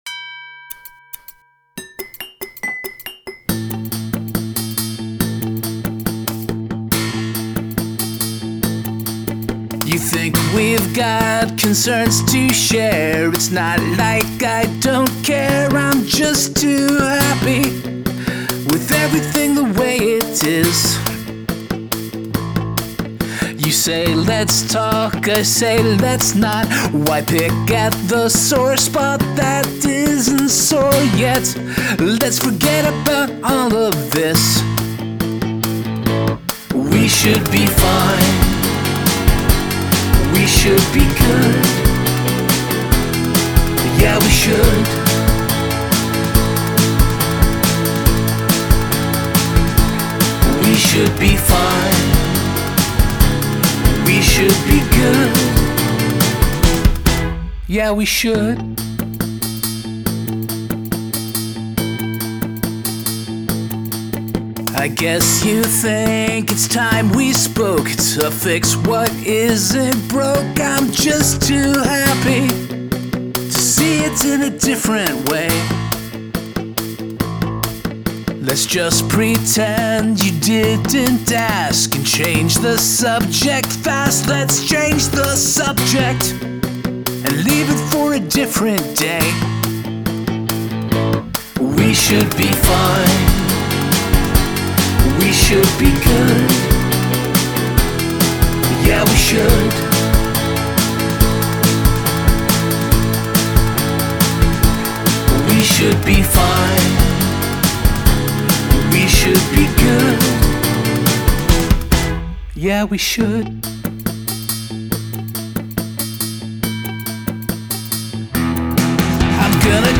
Have a guest play a household item on the track